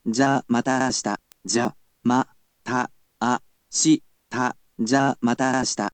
We’ll need to enlist the help of our lovely computer friend, QUIZBO™先生せんせい！
Click on the sound players to ask him to read something for you.